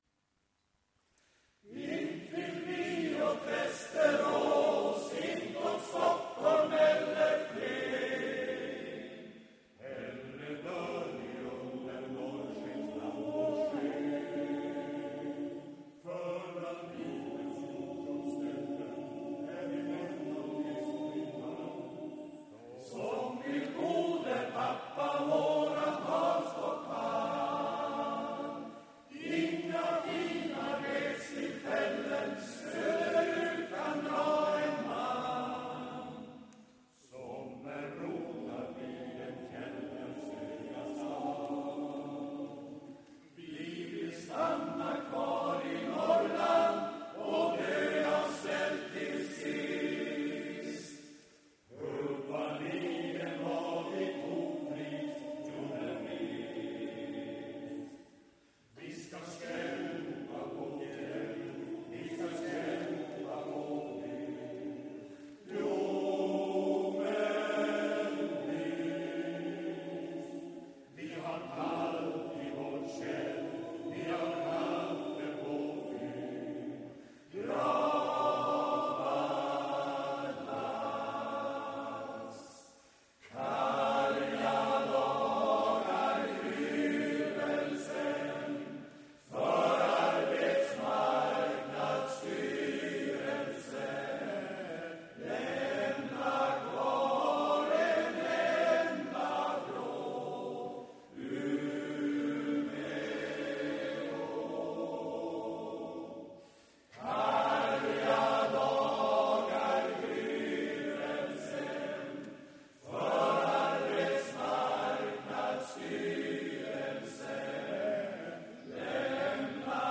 Konsert med Brolle - Backens Manskör
Inspelningen är gjord när kören hade en konsert ihop med Brolle i oktober 2008.